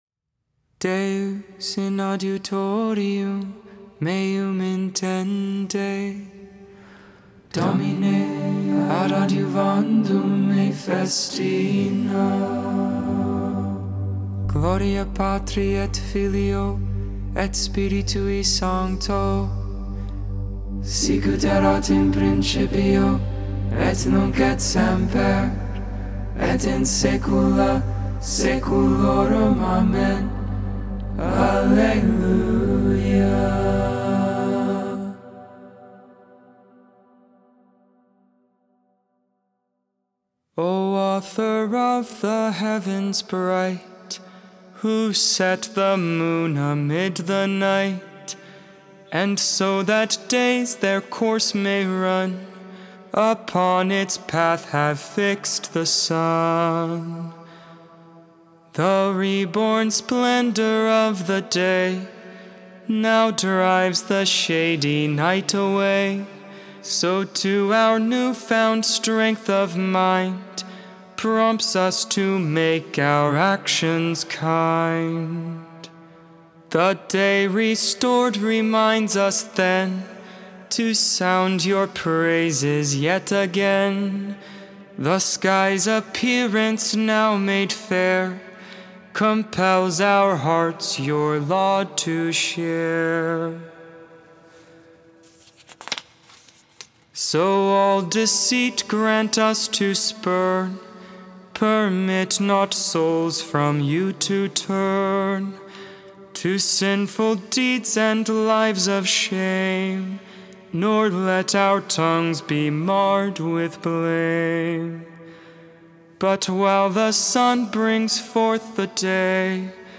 Polyphonic
Hymn
Psalm 77 (Gregorian tone 3a, drone G
Gregorian tone 8